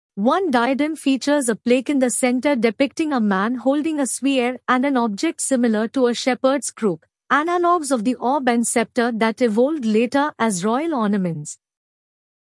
Text-to-Audio
Synthetic